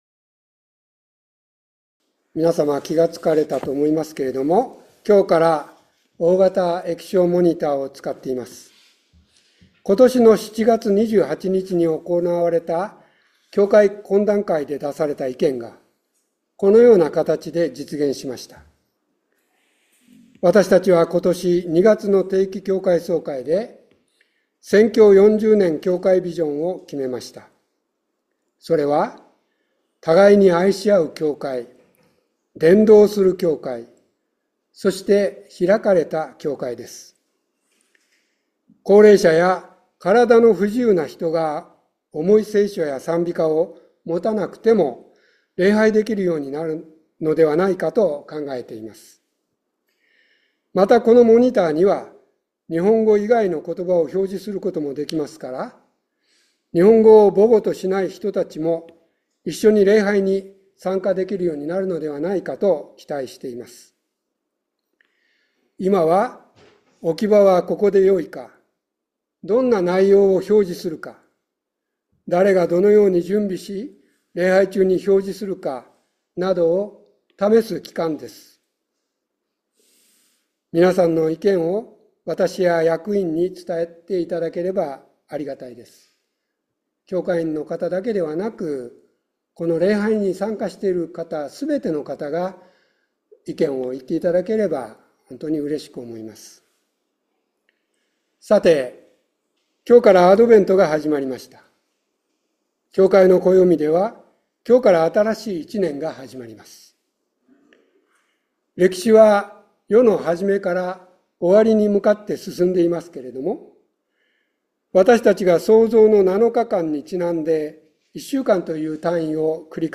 12月1日礼拝説教「キリスト到来の預言」